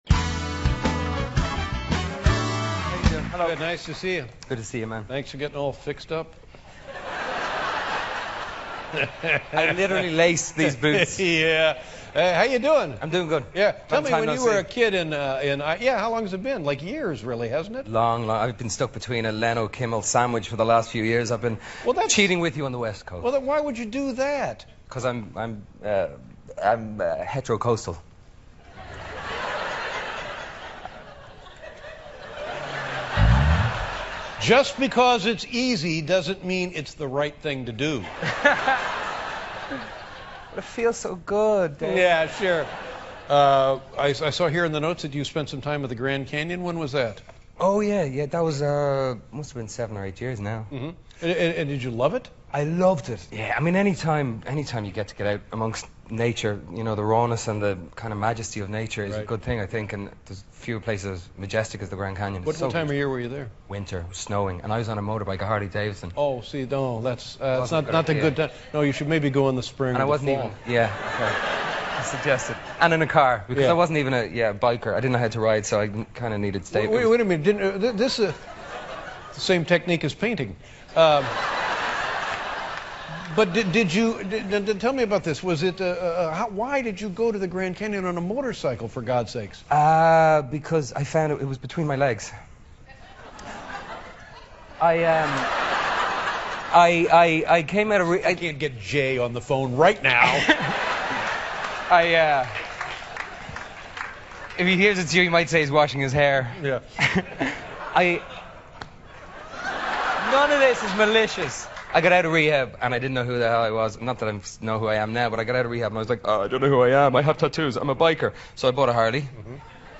访谈录 2011-08-17&08-19 柯林·法瑞尔的大峡谷之旅 听力文件下载—在线英语听力室